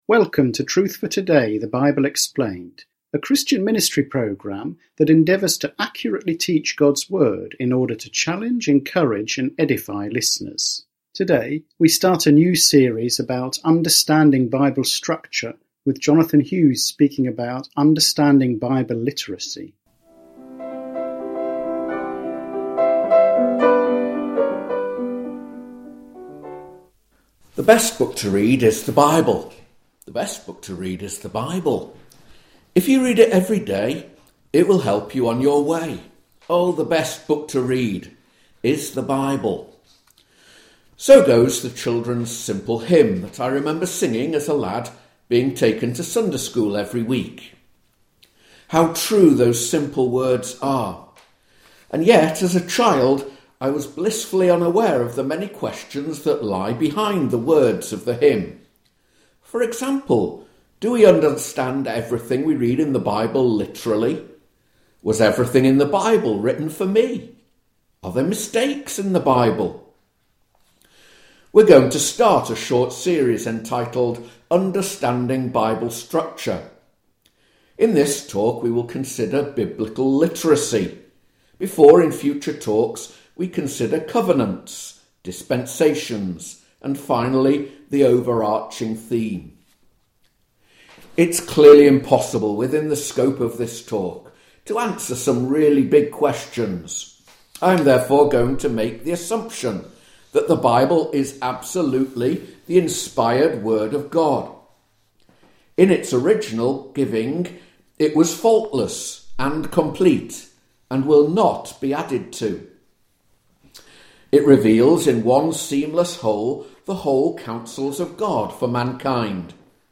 Truth for Today is a weekly Bible teaching radio programme.